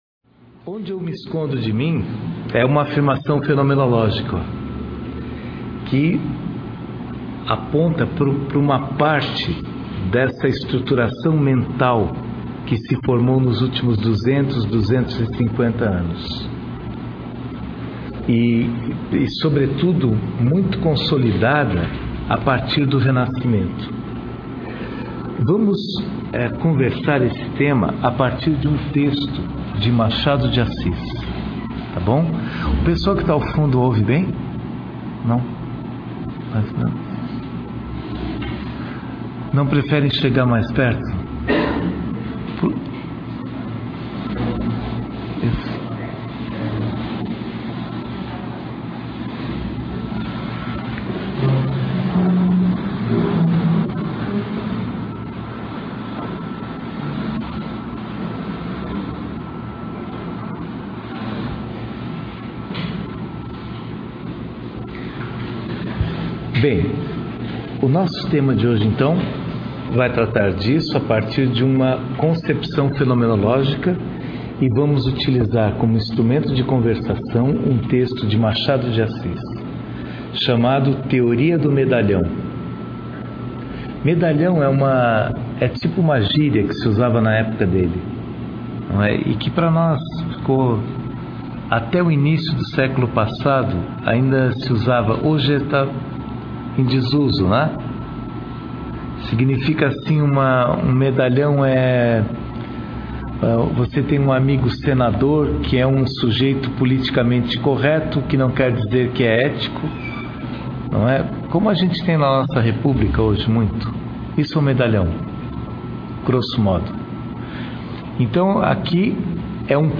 workshop em Cuiab�